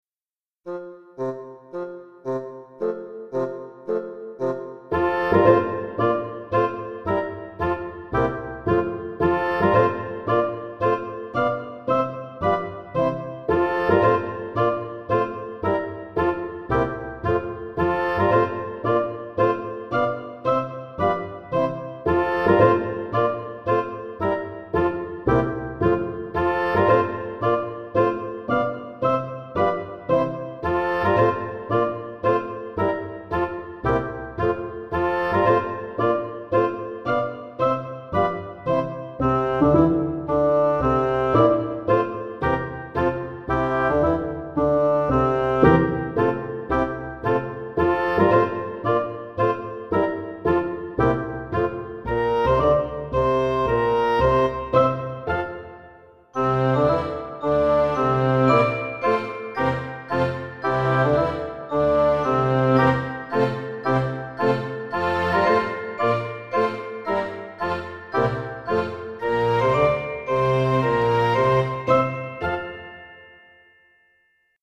Proponiamo La marcia del soldato in una versione didattica per flauto, con la base trascritta per fiati e archi.